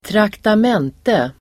Uttal: [²traktam'en:te]